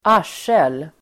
Uttal: [²'ar_s:el]